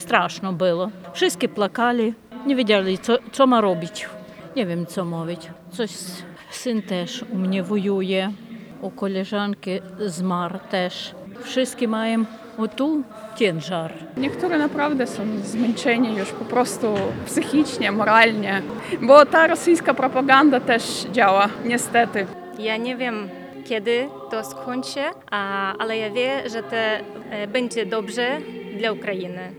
W trakcie wiecu odbyły się przemowy gości, odśpiewano hymny, a następnie zapalono znicze pod Pomnikiem Poległych Stoczniowców. Zebrani nie kryli emocji, przypominając o rozmiarach krzywdy.